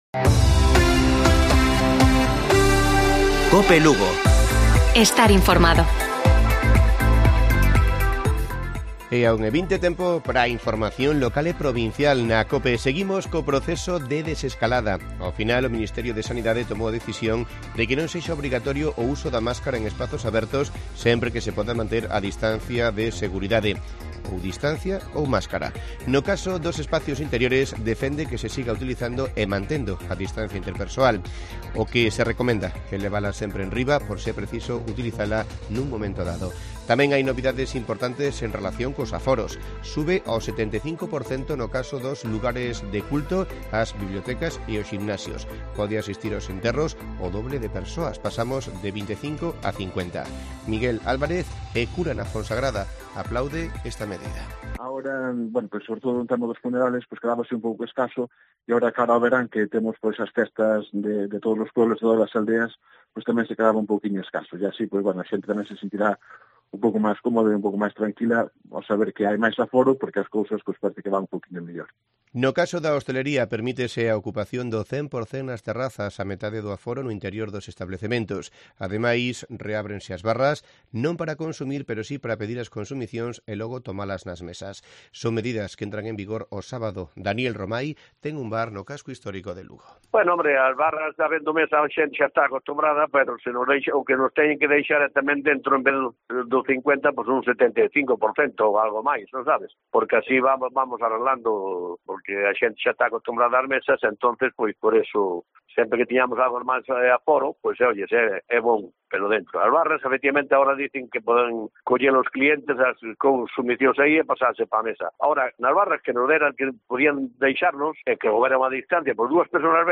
Informativo Provincial de Cope Lugo. 24 de junio. 13:20 horas